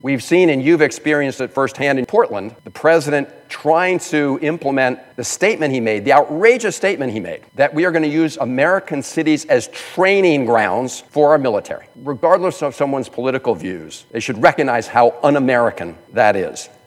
In a speech that had already exceeded 16 hours, Senator Jeff Merkley of Oregon held the Senate floor to protest aspects of President Donald Trump’s authoritarian style of leadership. In support of the speech, Merkley briefly yielded the floor to Maryland Senator Chris Van Hollen who spoke about Trump’s misuse of the military…